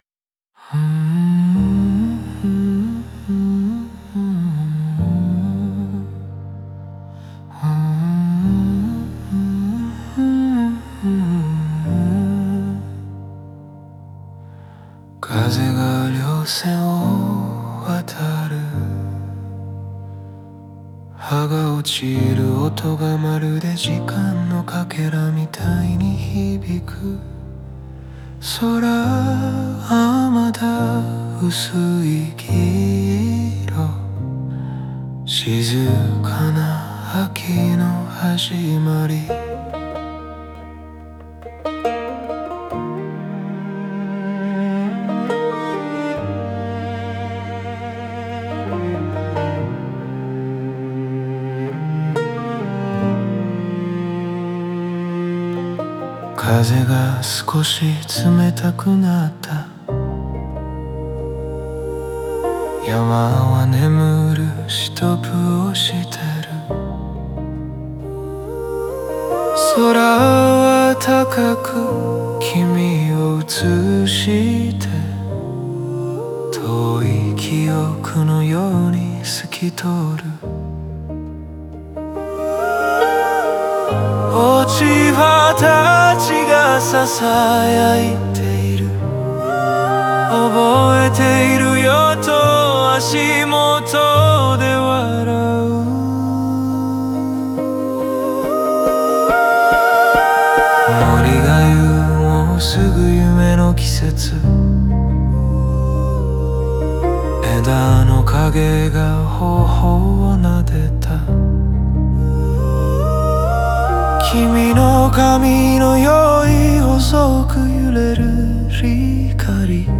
語りとコーラスが交錯し、「風＝記憶＝愛」というモチーフが全体を包み、別れの哀しさを越えて、優しさと永遠の余韻を残す。